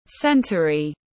Thế kỷ tiếng anh gọi là centery, phiên âm tiếng anh đọc là /ˈsen.tʃər.i/
Centery /ˈsen.tʃər.i/